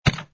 fs_ml_stone03.wav